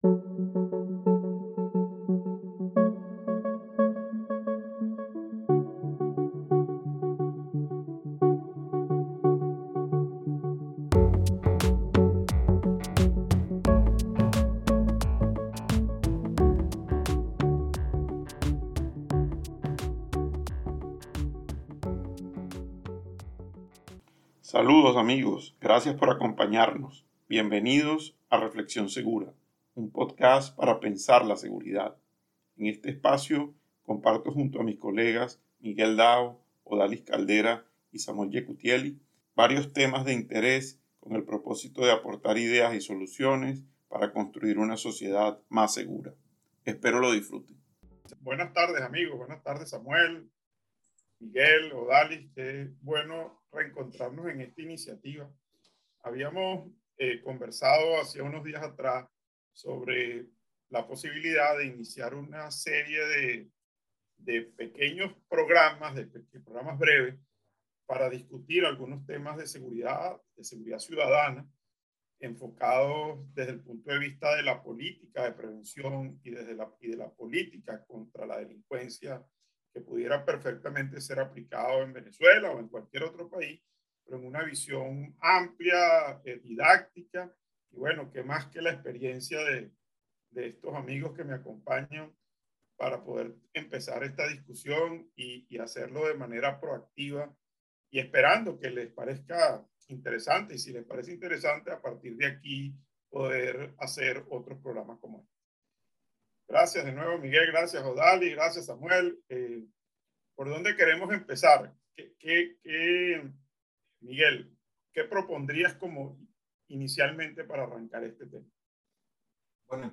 Este el primero de una serie de conversatorios